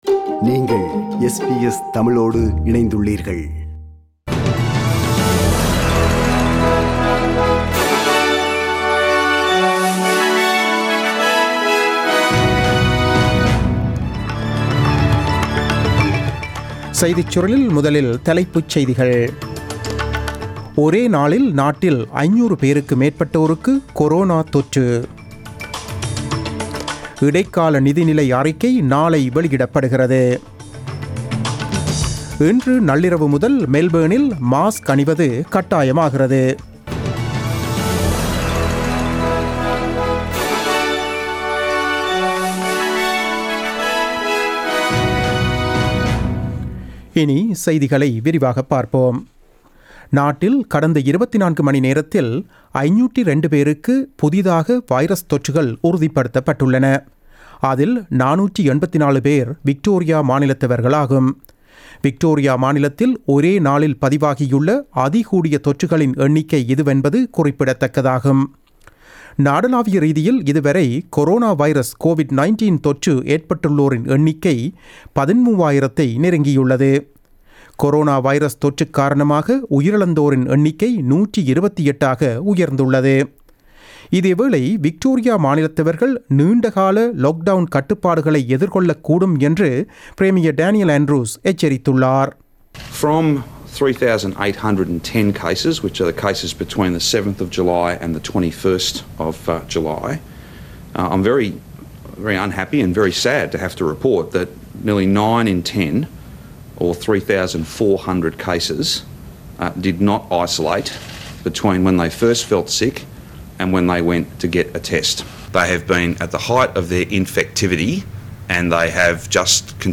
The news bulletin broadcasted on 22 July 2020 at 8pm.